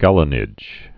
(gălə-nĭj)